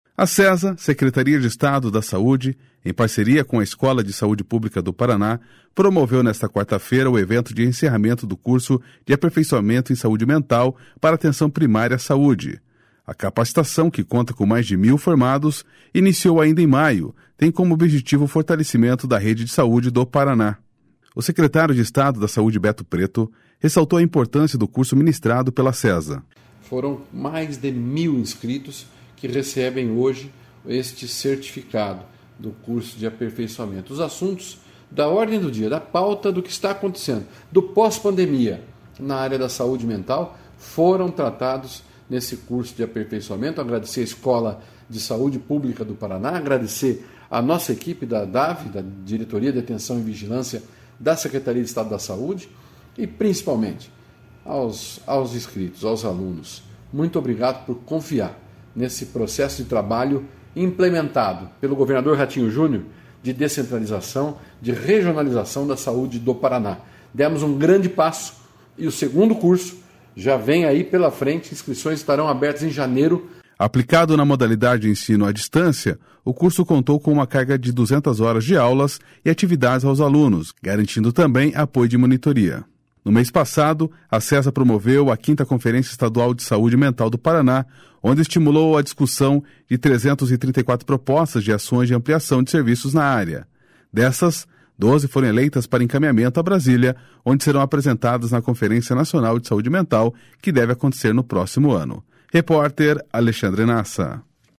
A capacitação, que conta com mais de mil formandos e iniciou ainda em maio, tem como objetivo o fortalecimento da Rede de Saúde do Paraná. O secretário de Estado da Saúde, Beto Preto, ressaltou a importância do curso ministrado pela Sesa.//Sonora Beto Preto//